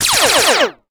EnemyLasers3.wav